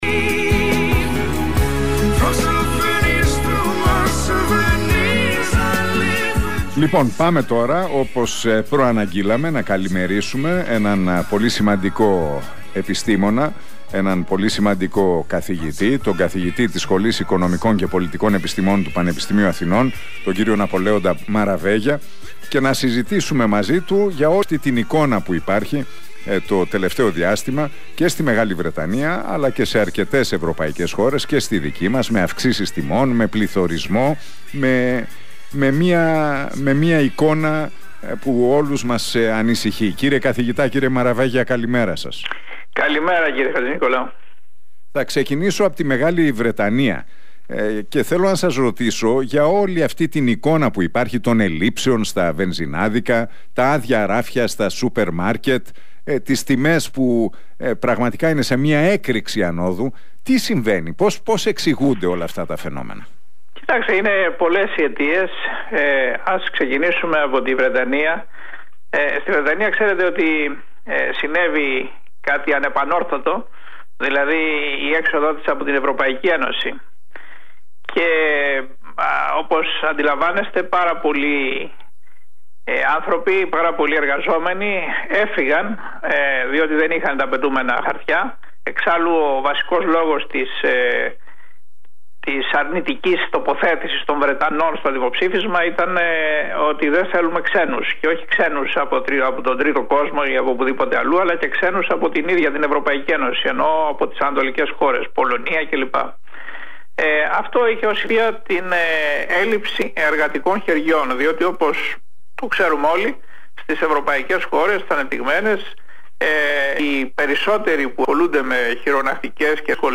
αναφέρθηκε στον πληθωρισμό και τις ανατιμήσεις μιλώντας στον Realfm 97,8 και στον Νίκο Χατζηνικολάου.